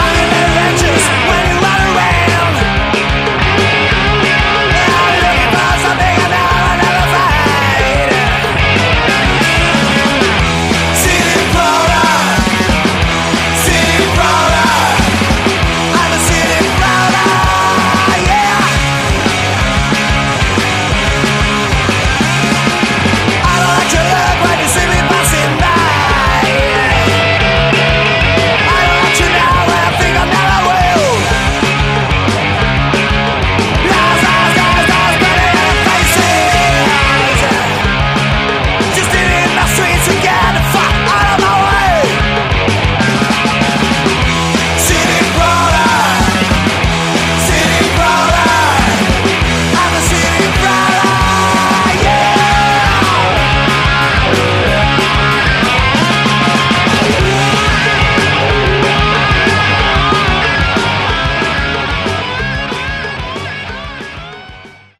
Category: Sleazy Hard Rock
vocals
bass
drums
guitars